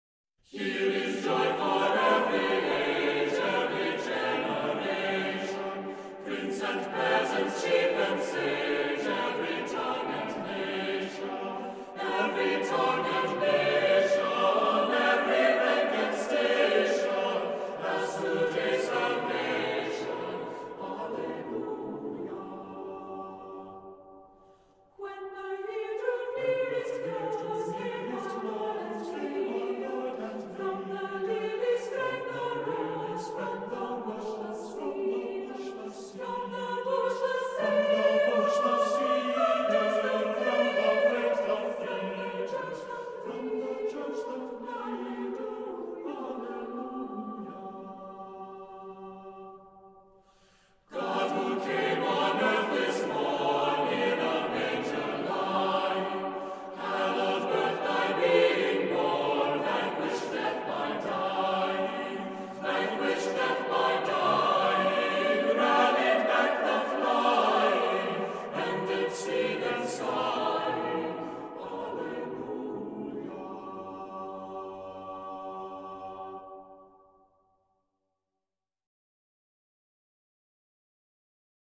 Voicing: Cantor,Assembly